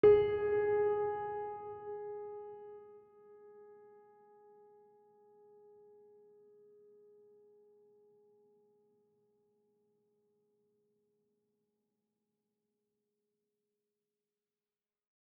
piano-sounds-dev
gs3.mp3